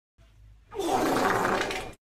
Sound Effects
Wet Fart Meme